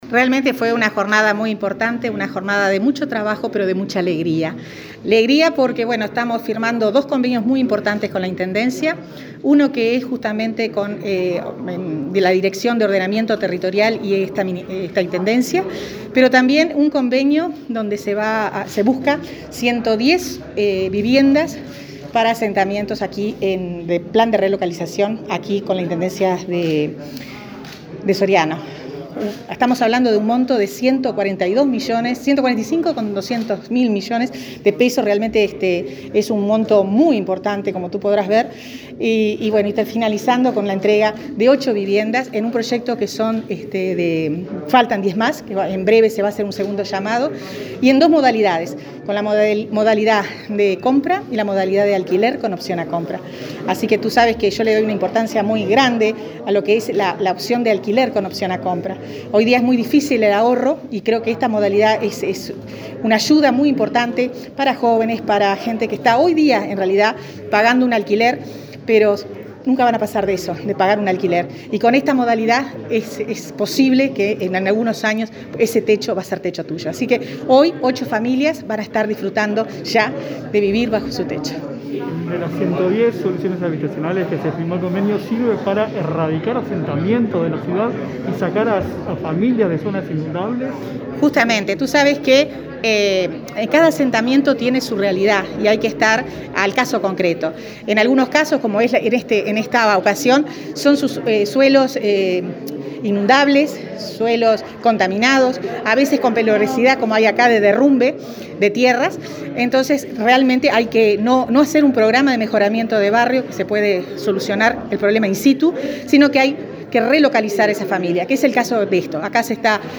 Palabras de la ministra Irene Moreira en Mercedes, Soriano